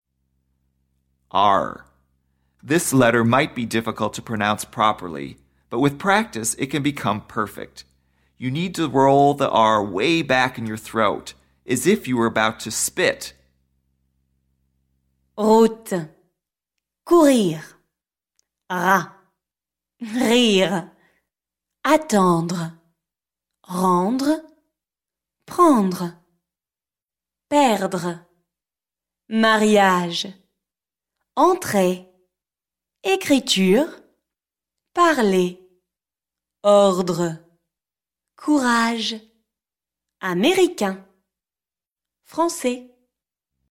You need to roll the “r” way back in your throat as if you were about to spit.